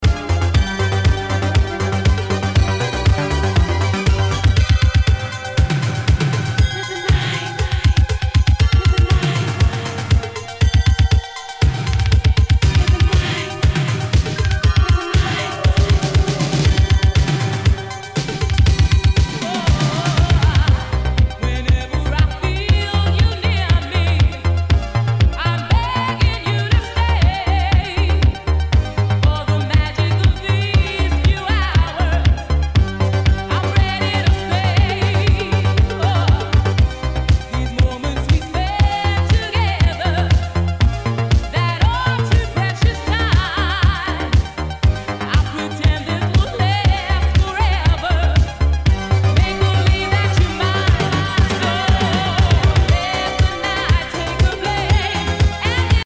SOUL/FUNK/DISCO
ナイス！ハイエナジー / シンセ・ポップ・ディスコ！